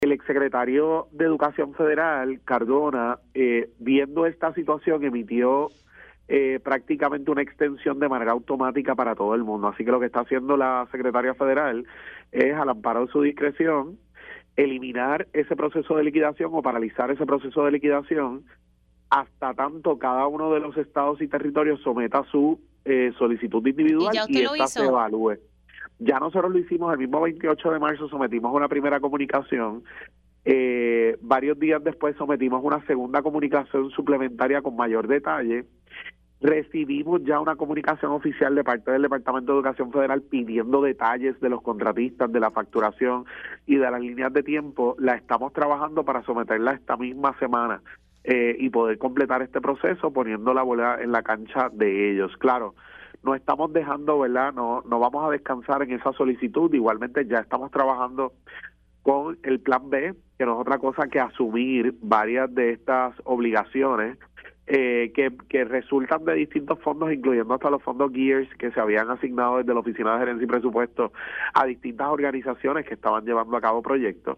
El secretario de Educación, Eliezer Ramos confirmó en Pega’os en la Mañana que ya se debe ver reflejado el aumento a los asistentes T-1 del Programa de Educación Especial en sus nóminas.